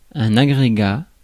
Ääntäminen
Ääntäminen France: IPA: [a.ɡʁe.ɡa] Haettu sana löytyi näillä lähdekielillä: ranska Käännös Ääninäyte Substantiivit 1. aggregate US US Suku: m .